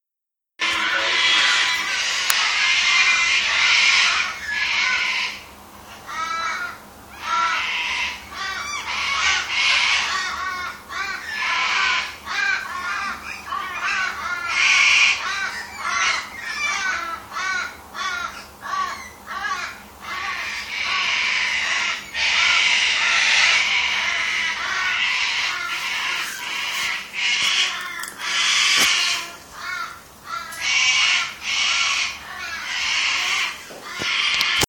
Cocky chorus with a crow solo
The chorus is made up of about ten Sulphur-crested Cockatoos, who politely allowed a few blow-ins (Australian ravens, aka crows) to take a couple of solos.
The spectrum points to the crow because the cockies, admirable birds though they are, put out a blast right across the spectrum and therefore qualify more as noise than music.
cocky-chorus.mp3